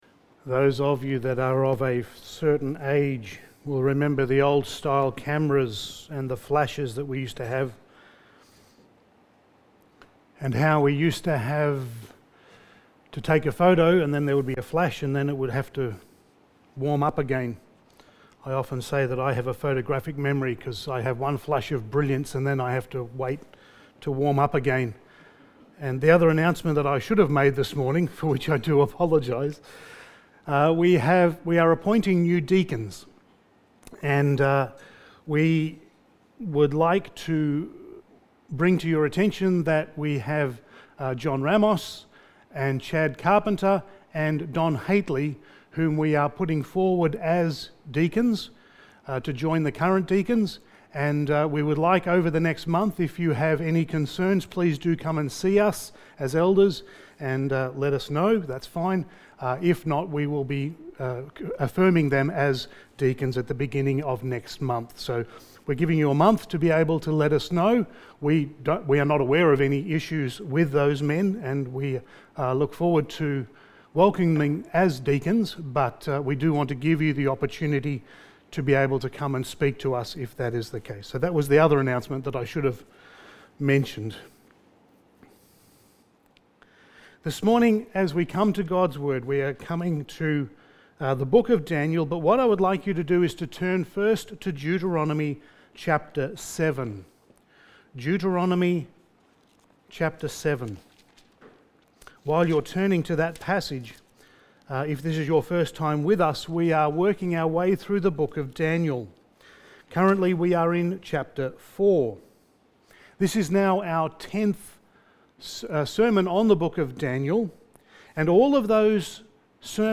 Passage: Daniel 4:28-37 Service Type: Sunday Morning